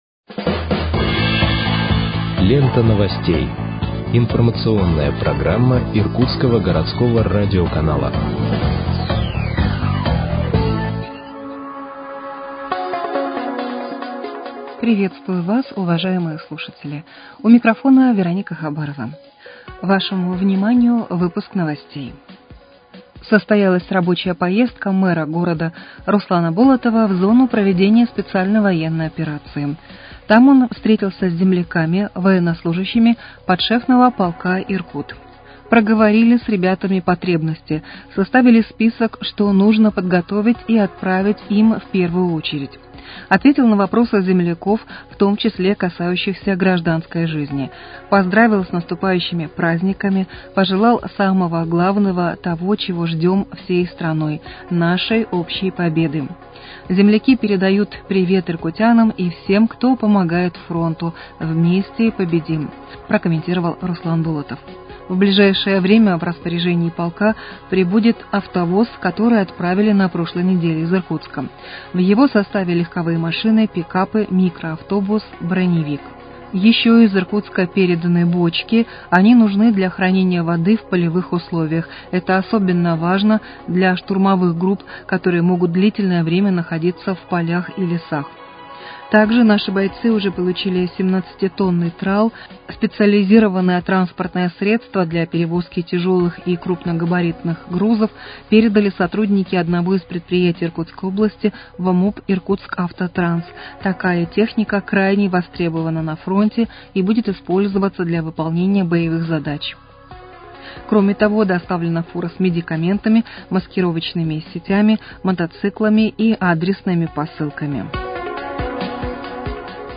Выпуск новостей в подкастах газеты «Иркутск» от 18.12.2025 № 1